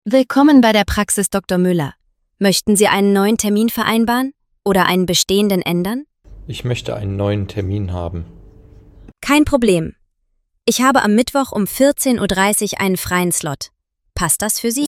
Exzellenter Kundenservice ist unsere Leidenschaft mit den natürlich menschlichen Stimmen unserer Sprachassistenten.
Sprachbeispiel: